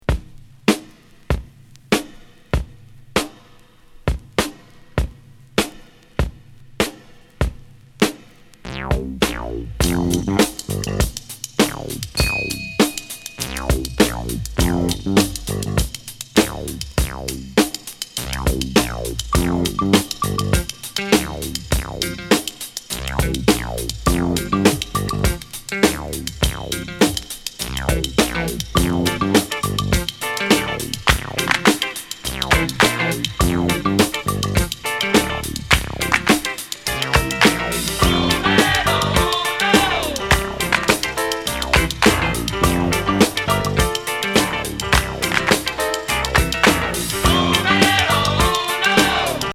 77年アーバンなラテン・ジャズ・ファンク作!